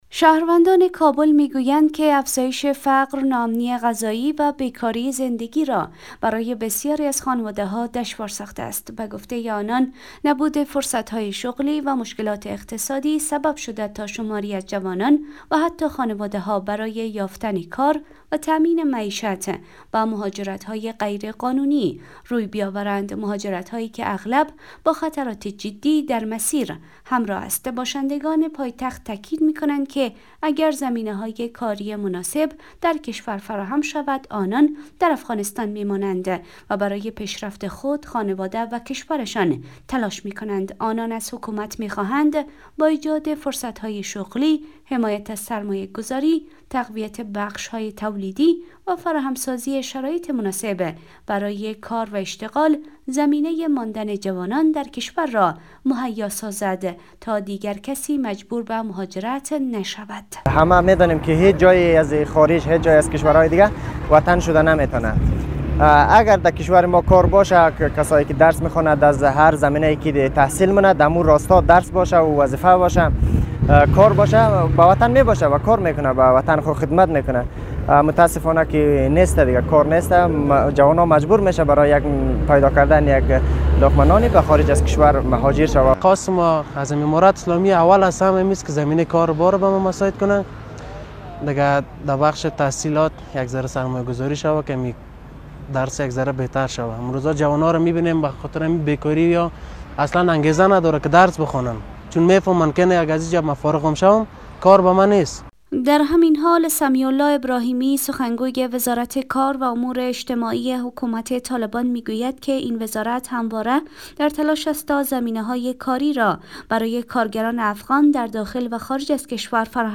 گزارش فقر در افغانستان